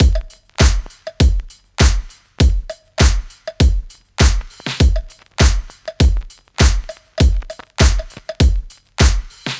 Unison Funk - 1 - 100bpm.wav